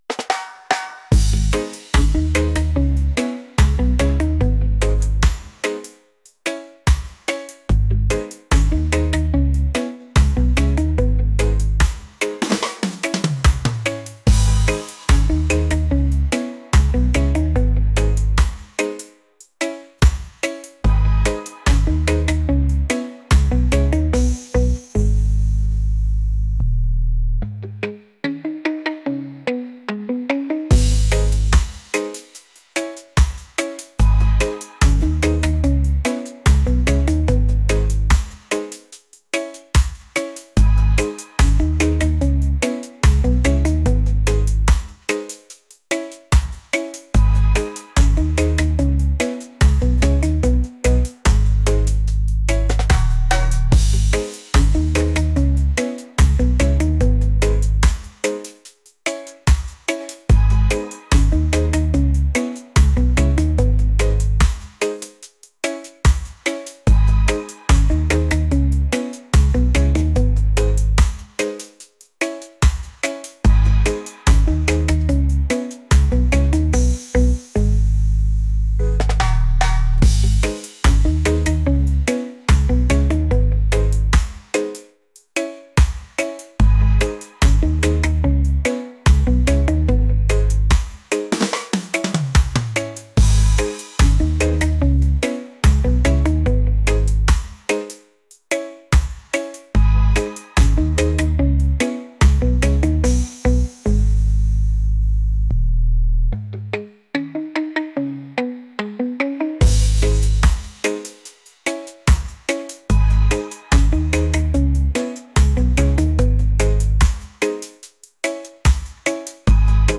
reggae | lofi & chill beats | soul & rnb